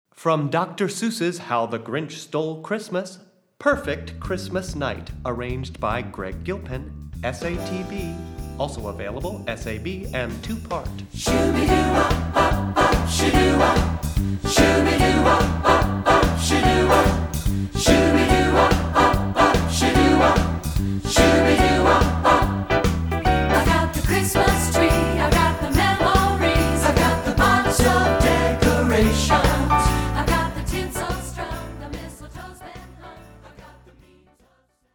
Voicing: SSAB